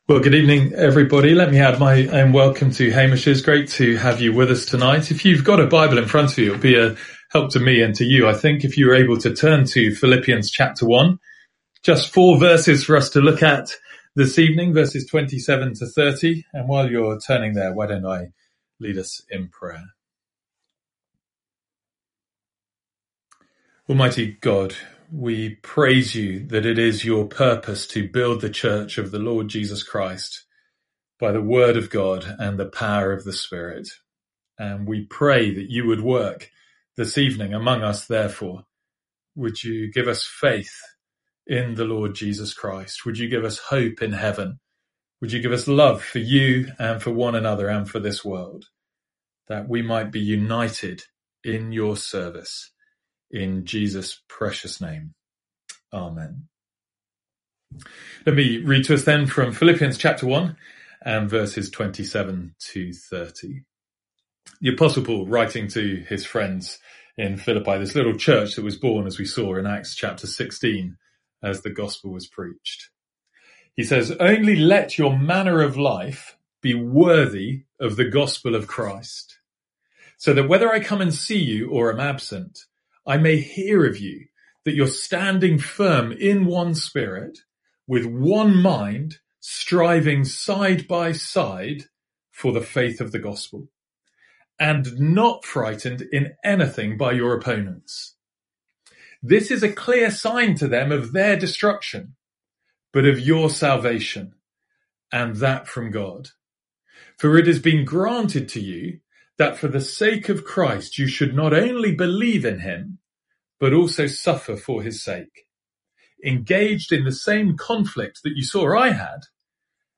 From our evening service in Philippians.